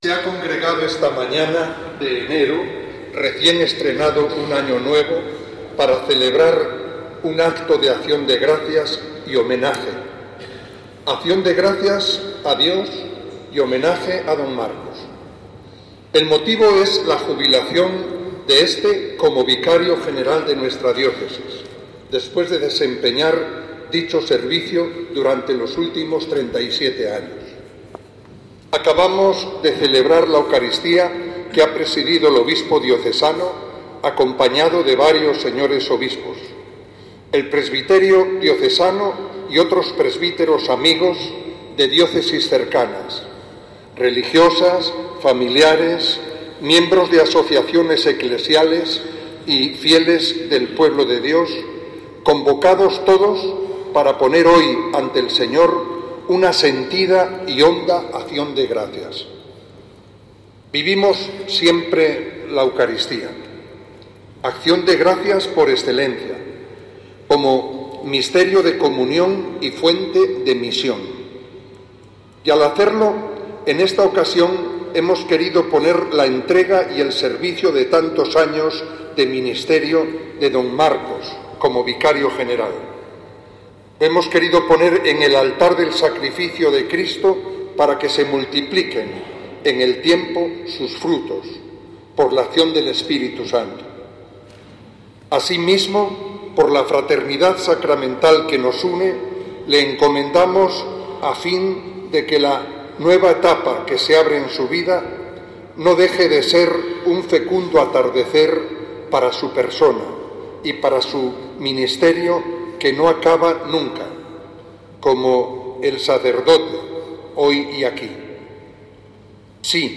HOMILÍA DE MONS. JUAN ANTONIO MENÉNDEZ